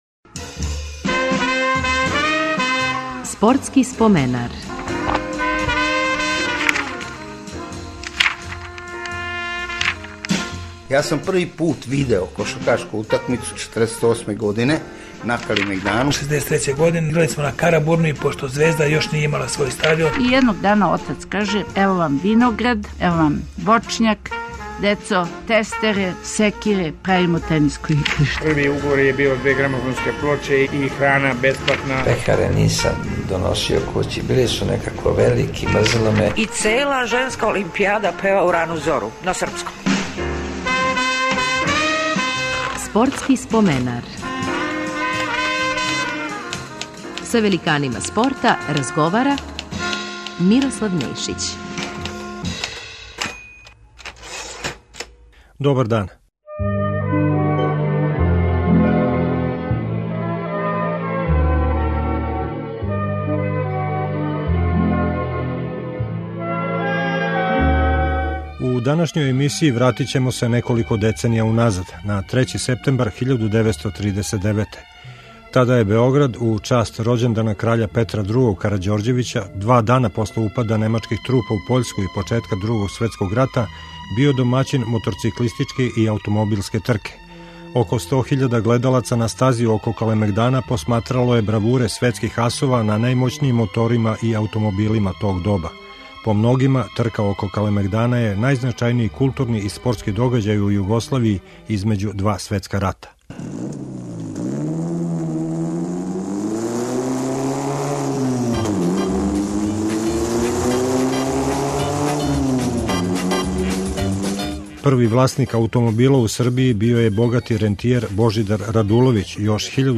Репризираћемо емисију посвећену ауто-мото тркама око Калемегдана, а повод је 75 година од одржавања прве трке - 3. септембар 1939.